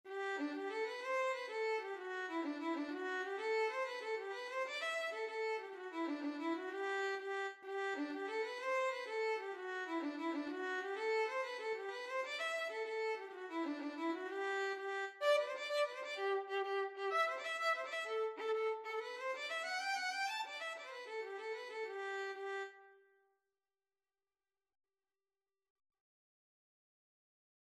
G major (Sounding Pitch) (View more G major Music for Violin )
6/8 (View more 6/8 Music)
Violin  (View more Intermediate Violin Music)
Traditional (View more Traditional Violin Music)
Irish